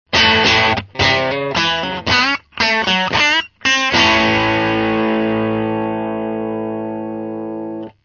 (NoEQ,NoEffectで掲載しています）
No.2 MP3 ★★ GeorgeL'sアンプ直、です。
Canareと比べて、ケーブル長が短いのを差し引いてもクリアなキャラクタが聴いて取れます。
中高音域がはっきりとした感じで前に出てきました。